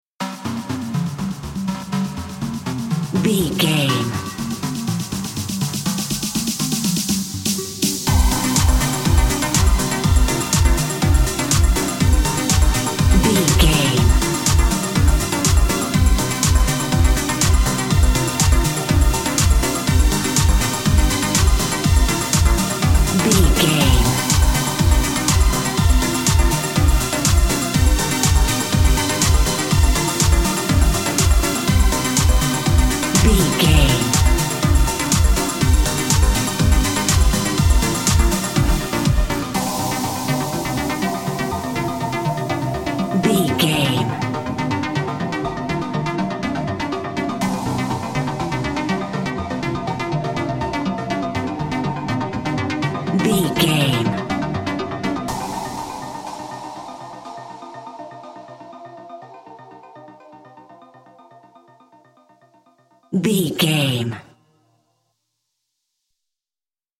Aeolian/Minor
groovy
uplifting
energetic
synthesiser
drum machine
house
synth leads
synth bass
uptempo